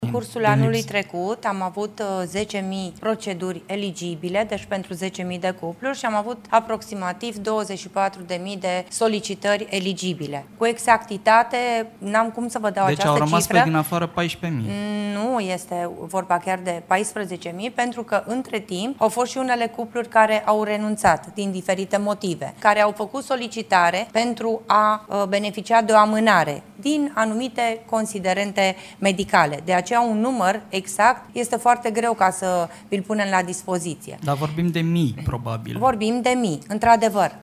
Ministrul Familiei, Natalia Intotero: „Au fost și unele cupluri care au renunțat din diferite motive”